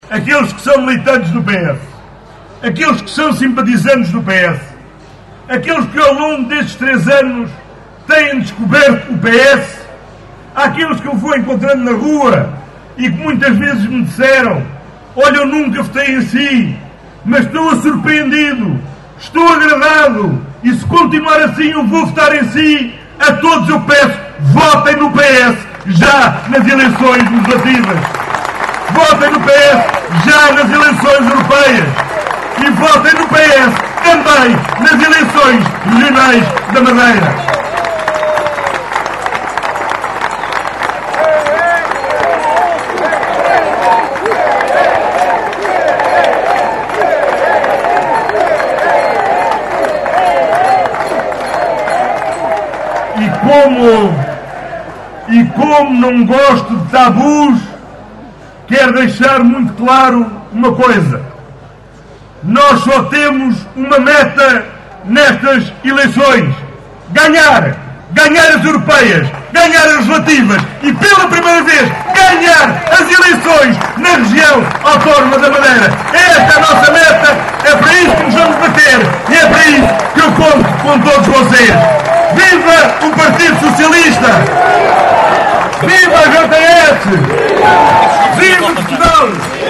Os socialistas rumaram a norte no passado sábado (25 de agosto) para a habitual “rentrée” política que este ano teve lugar no Parque Municipal em Caminha.